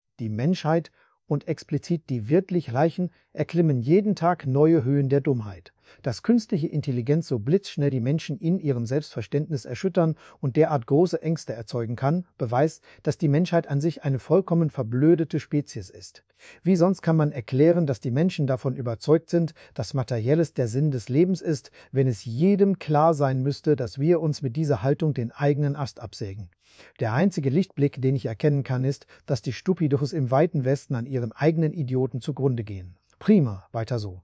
hier dein text mit der stimme von olaf scholz - hat 29 sec. zum rendern gedauert. mit einem bisschen gefummel würde man das noch emotionaler kriegen...